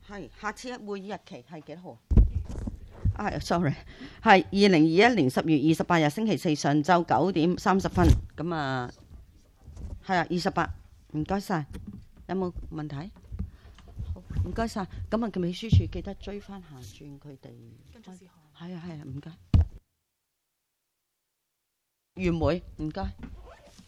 委員會會議的錄音記錄